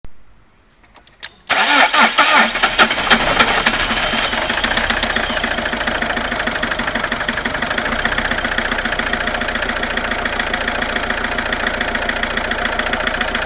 La macchina fatica ad accendersi soprattutto la mattina e tra i 2000 e 3000 giri ha delle turbolenze, non tira come una volta, oggi sono arrivato a 140 senza difficolta ma ha perso la grinta.
Ho allegato un file con la registrazione di una accensione la mattina, in questa occasione si è messa in moto subito in altre è molto più dura e bisogna insistere un bel pò.